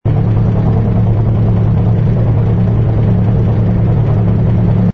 engine_pi_fighter_loop.wav